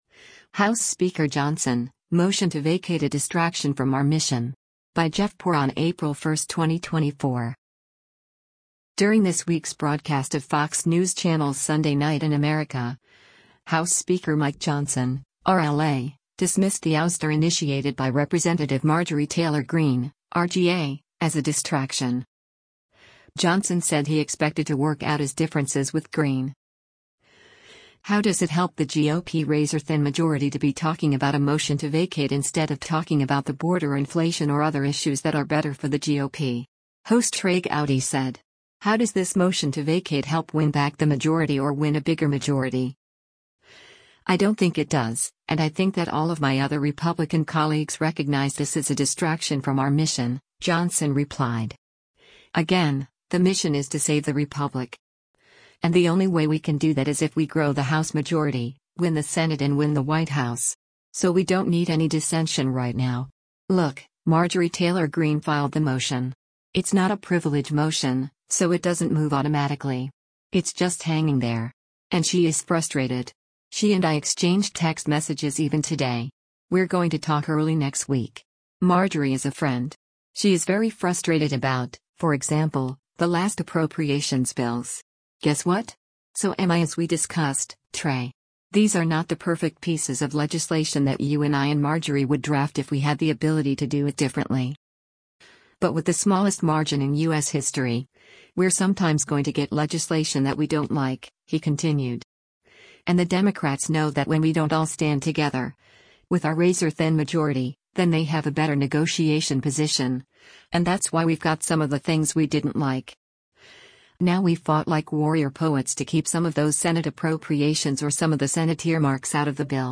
During this week’s broadcast of Fox News Channel’s “Sunday Night in America,” House Speaker Mike Johnson (R-LA) dismissed the ouster initiated by Rep. Marjorie Taylor Greene (R-GA) as a “distraction.”